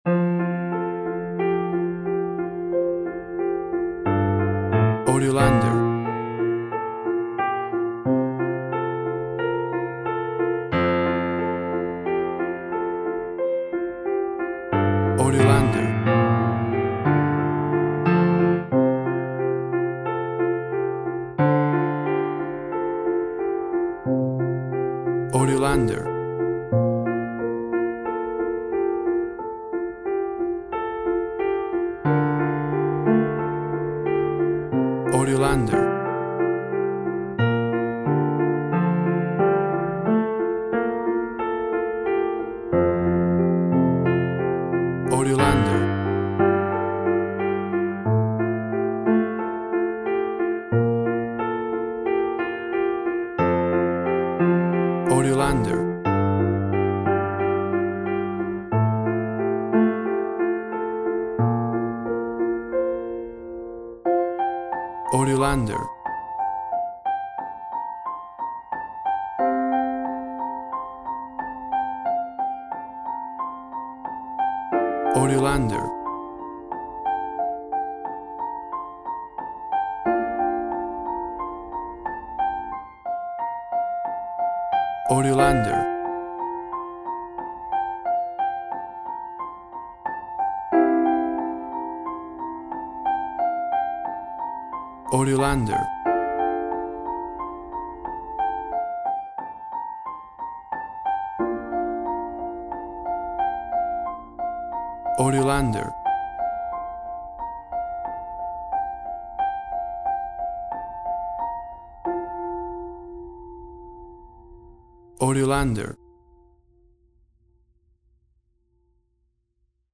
WAV Sample Rate 16-Bit Stereo, 44.1 kHz
Tempo (BPM) 90